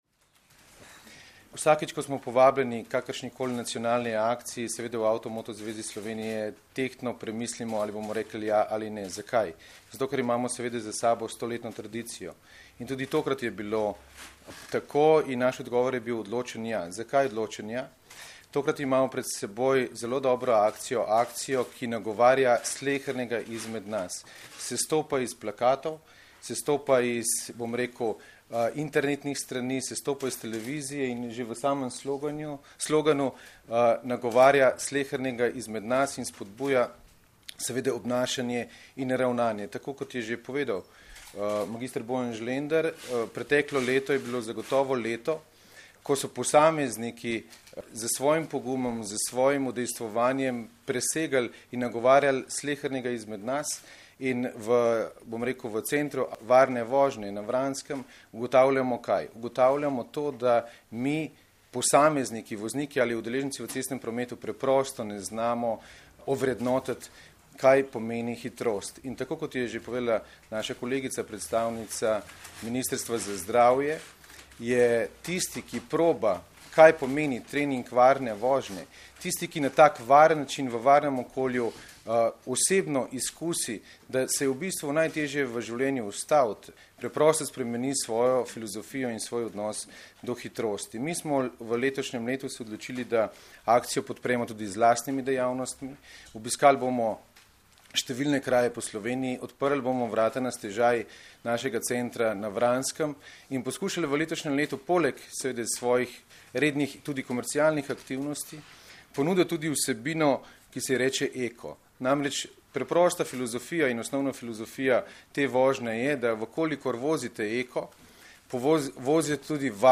Policija - Policisti začeli s poostrenim nadzorom hitrosti - preventivna kampanja Hvala, ker voziš zmerno - informacija z novinarske konference
Zvočni posnetek izjave